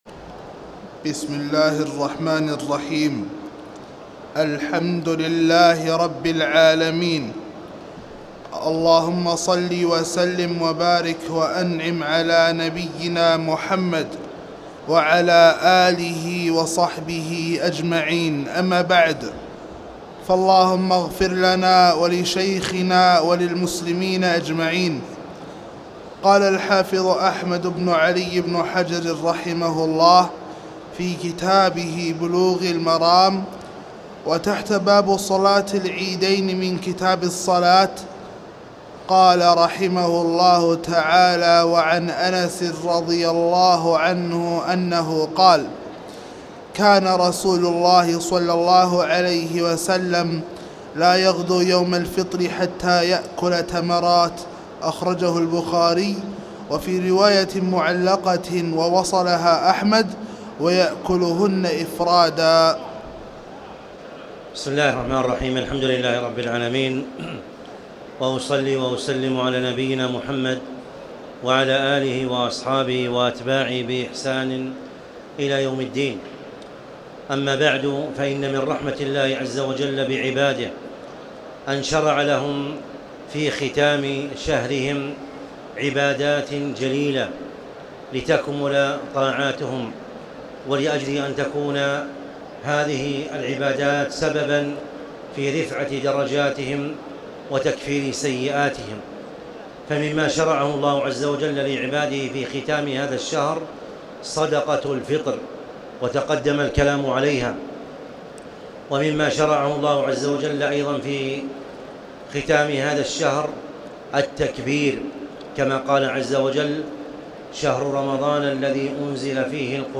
تاريخ النشر ٢٨ رمضان ١٤٣٨ هـ المكان: المسجد الحرام الشيخ